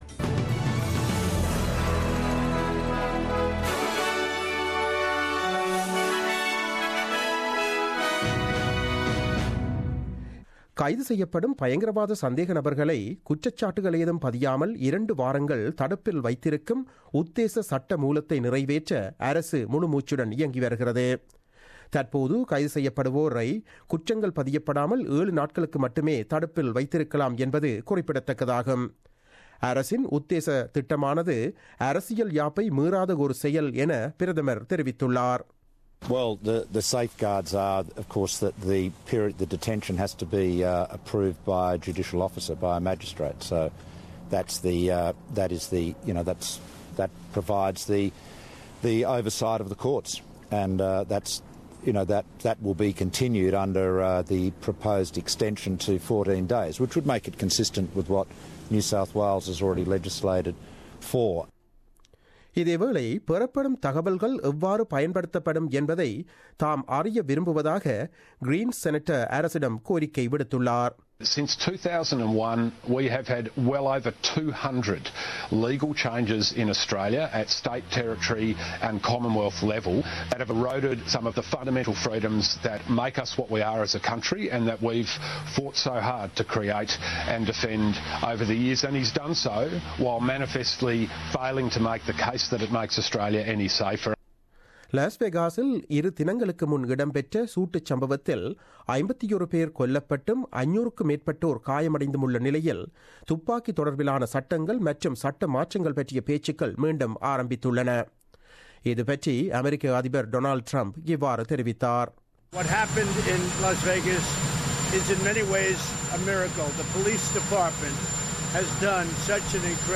04/10/2017 Australian News